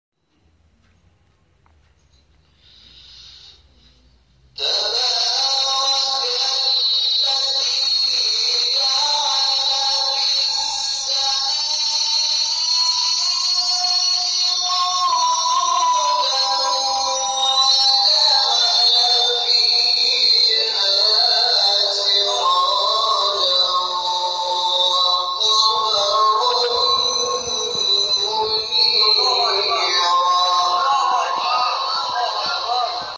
نغمات صوتی از قاریان ممتاز کشور
در مقام راست